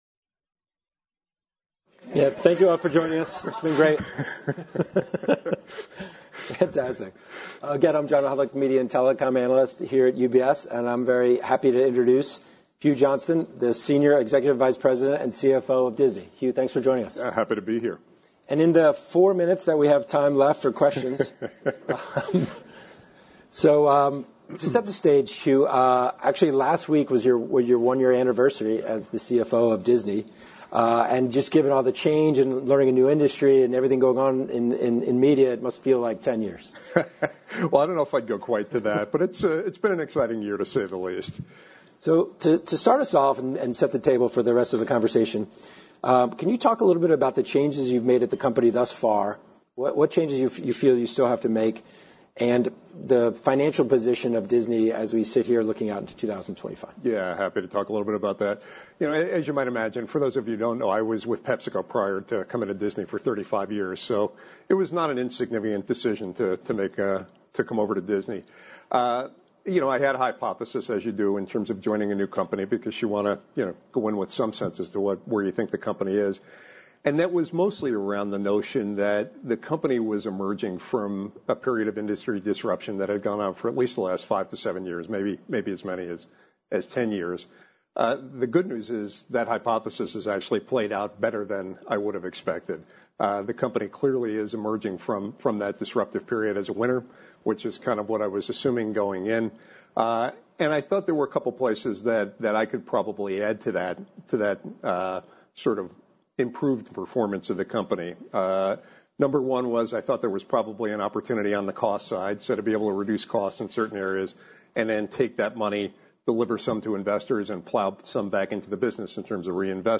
The Walt Disney Company at the UBS Global Media and Communications Conference